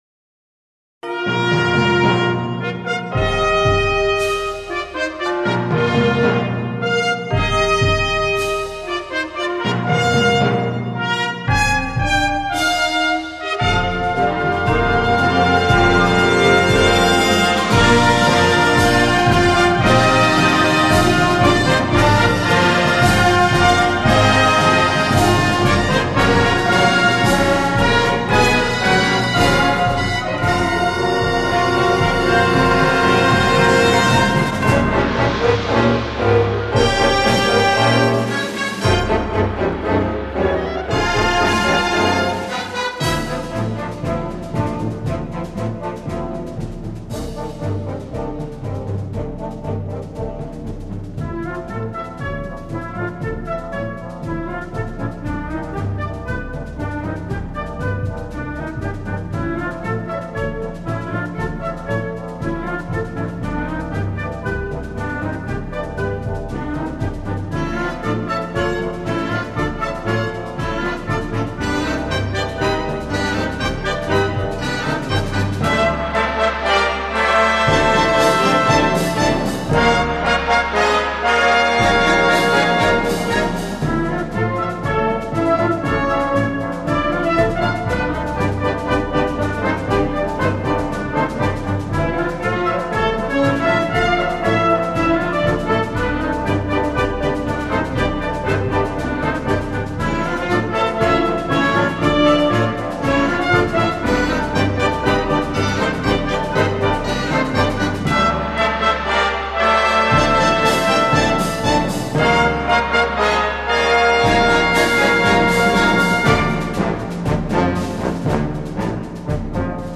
Gender: Moorish marches